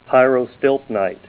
Help on Name Pronunciation: Name Pronunciation: Pyrostilpnite + Pronunciation